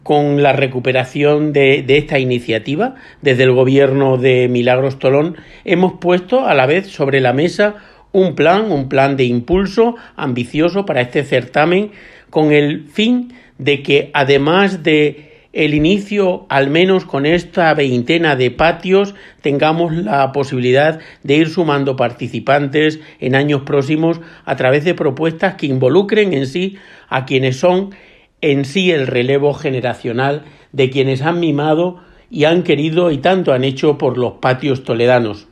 AUDIOS. Teo García, concejal de Cultura y Patrimonio Histórico